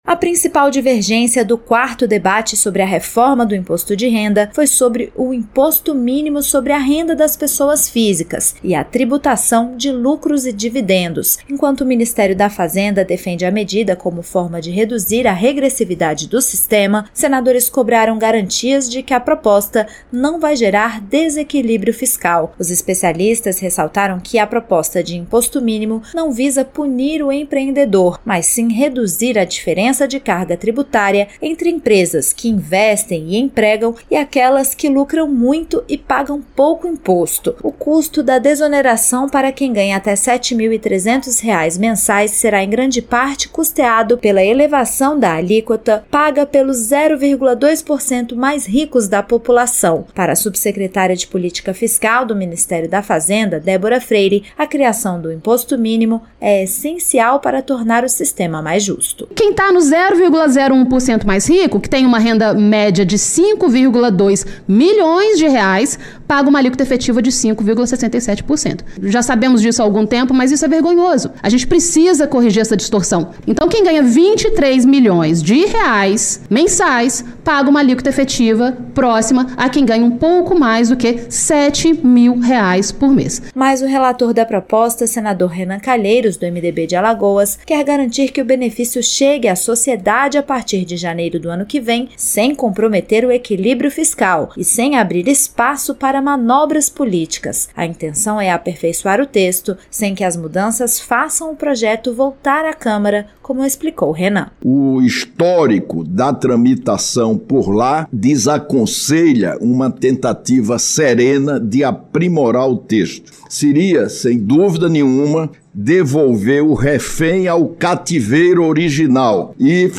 Economia